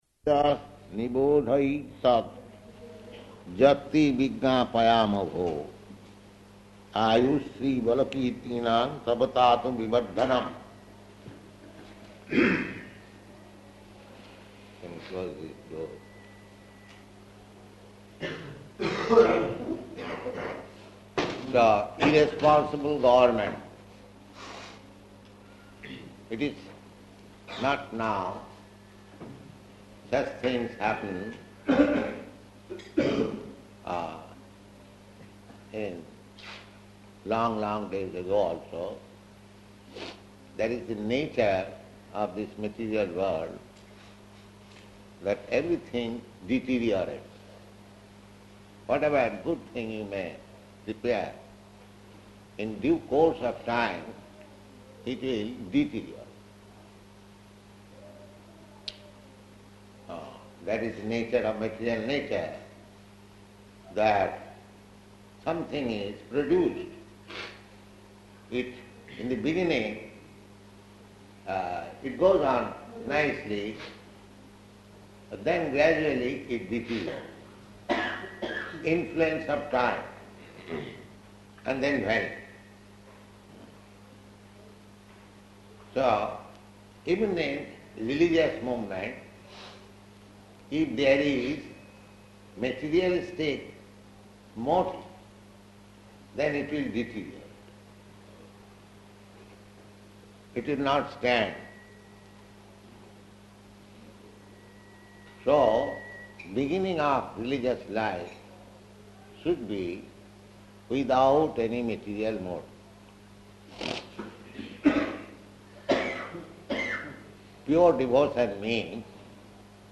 Type: Srimad-Bhagavatam
Location: Delhi